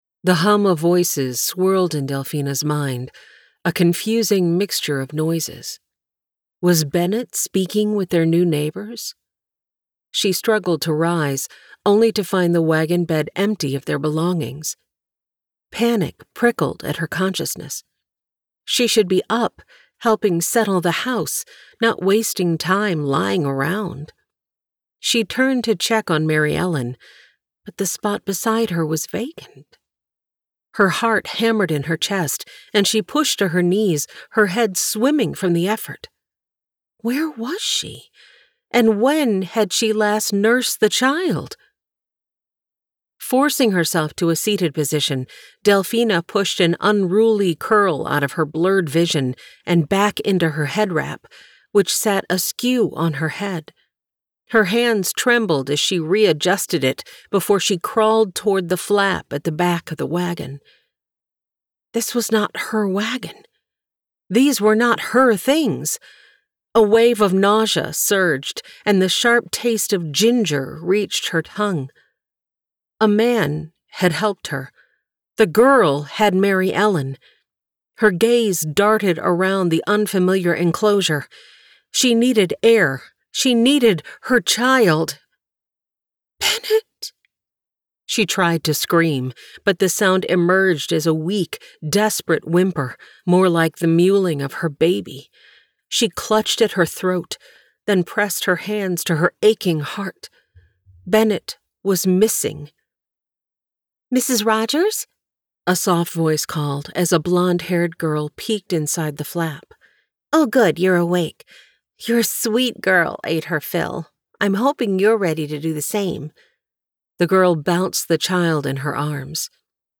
Sample Audiobook | Buy Audiobook